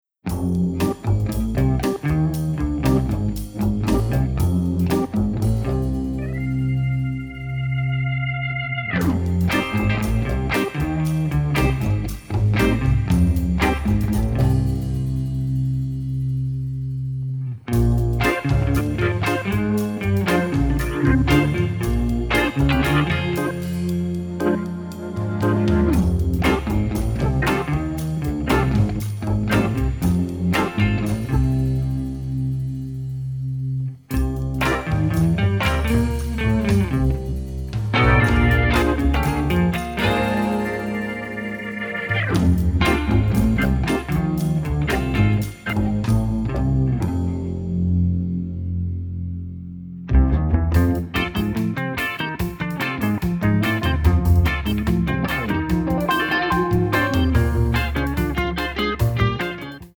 encompassing tenderness, sadness and nostalgia